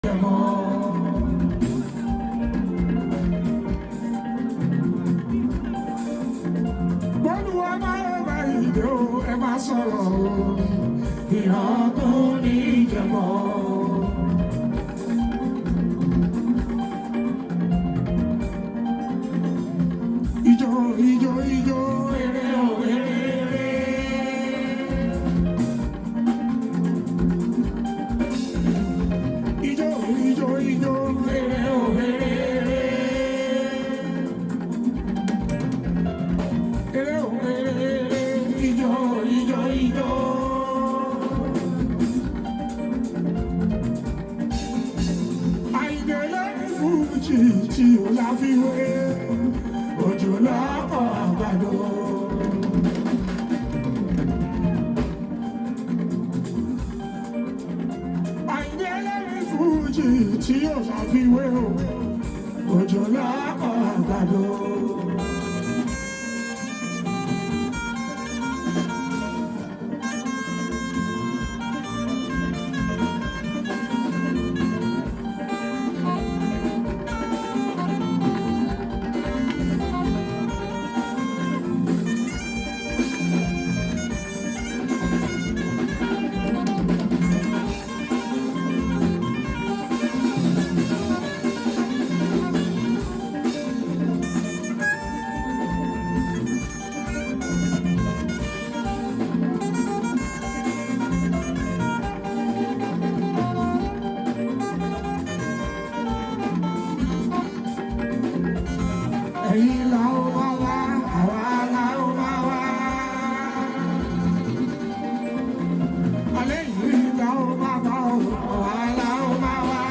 Yoruba Fuji song